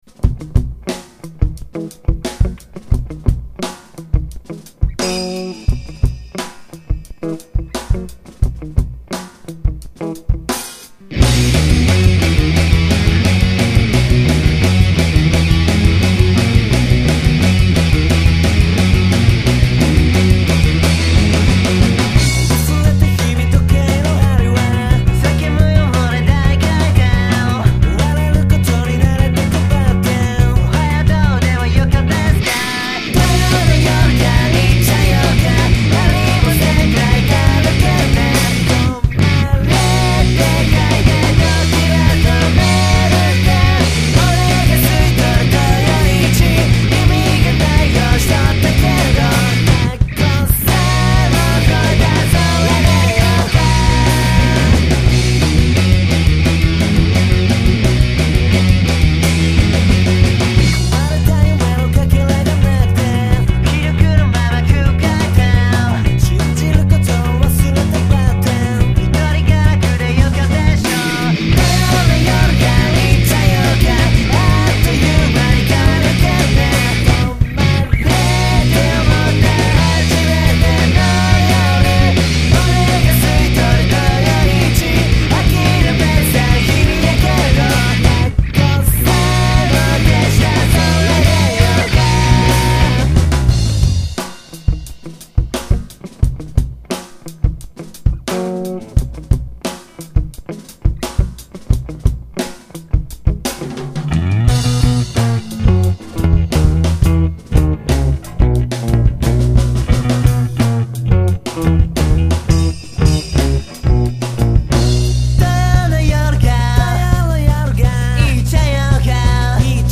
方言全開です。
そして弟とのツインボーカル全開です。
ヘッドフォンで聴くとおもに左から聞こえるのが弟、右が俺です。